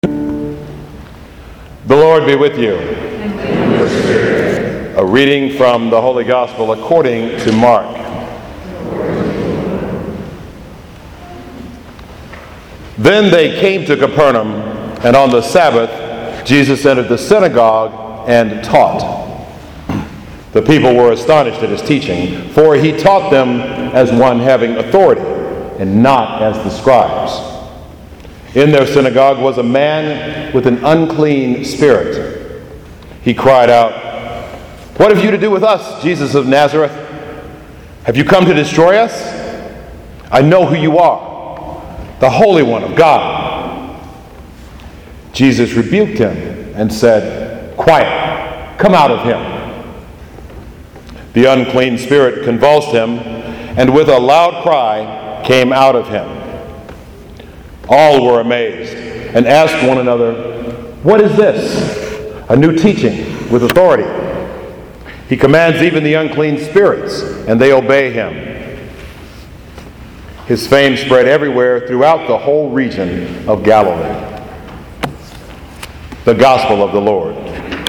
The Gospel for the day is
gospel-4th-sunday-in-ordinary-time-cycle-b.mp3